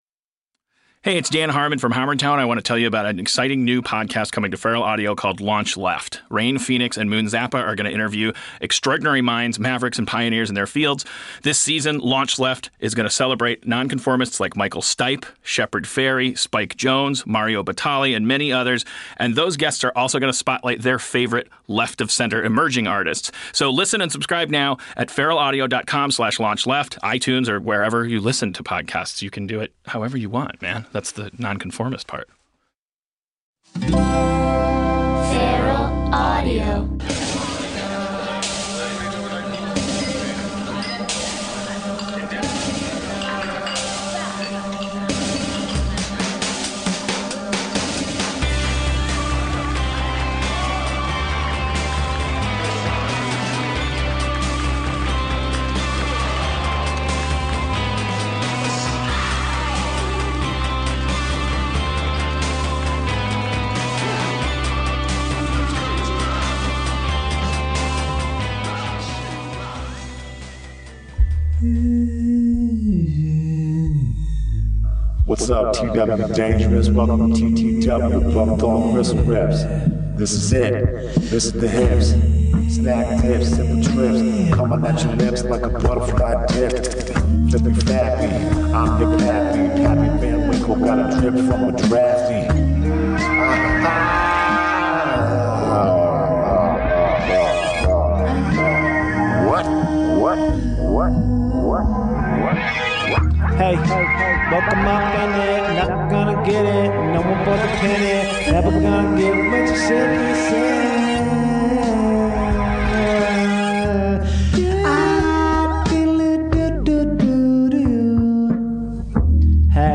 A soloist episode with some calls and collages and personal reflections and character talks.